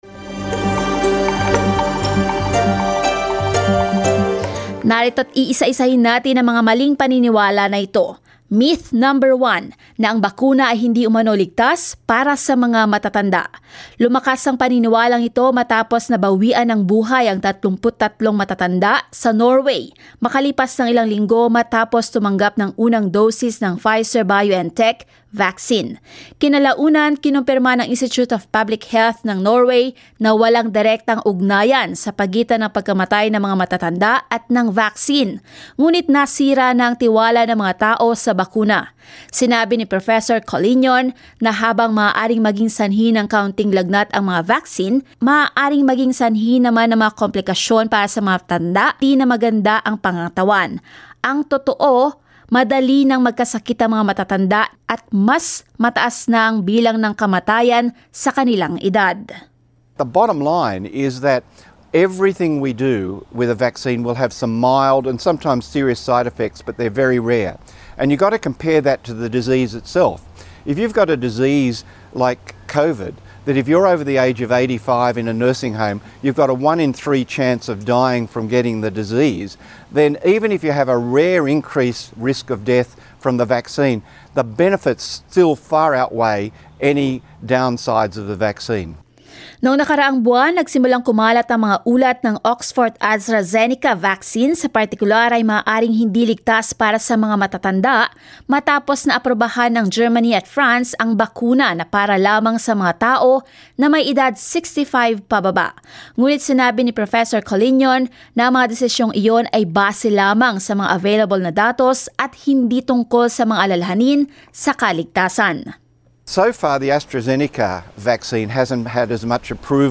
Sa ulat na ito, aalamin natin ang mga kumakalat na maling paniniwala tungkol sa mga bakuna.